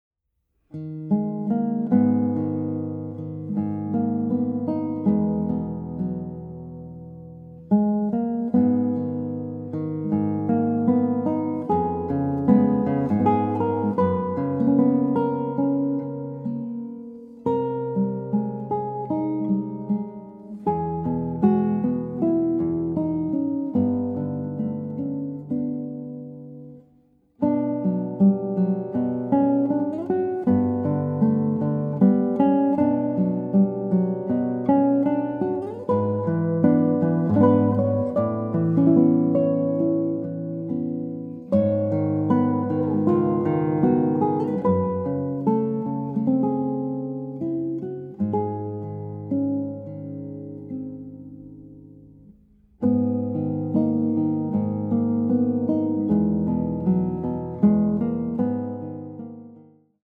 Images become sound – five world premieres for guitar